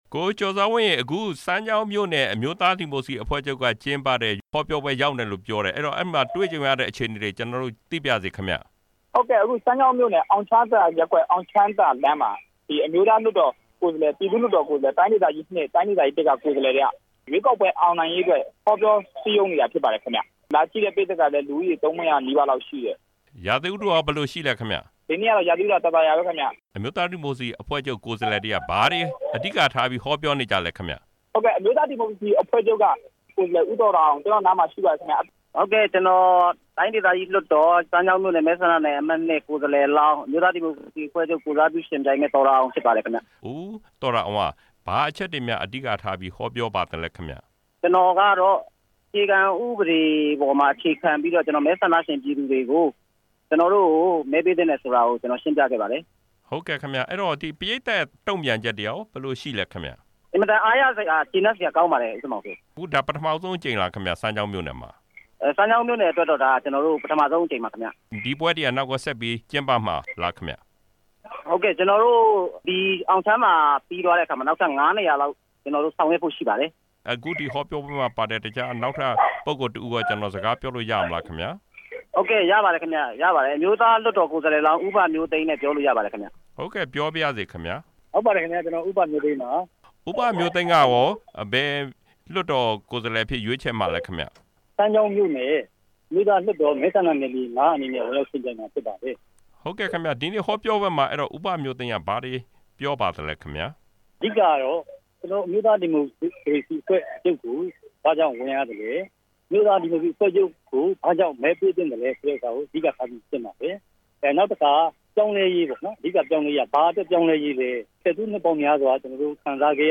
စမ်းချောင်းမြို့နယ် NLD ဟောပြော ပွဲအကြောင်း မေးမြန်းချက်